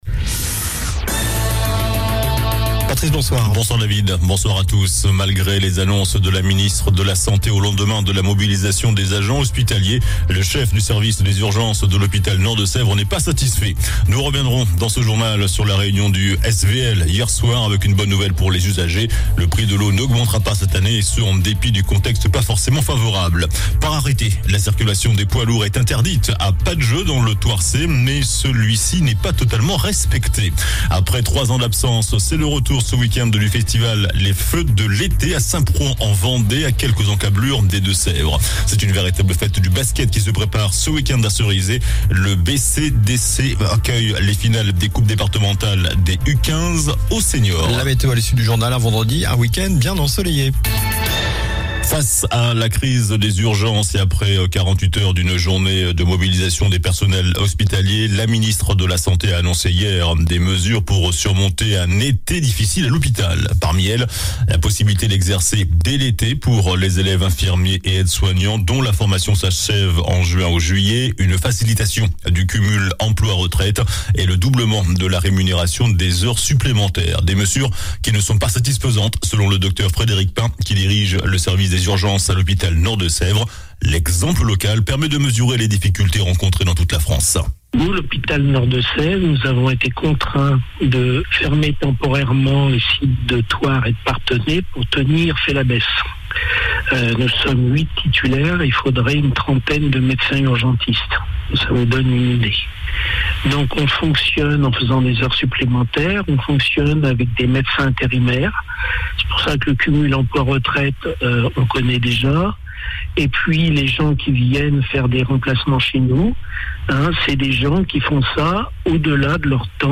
JOURNAL DU JEUDI 09 JUIN ( SOIR )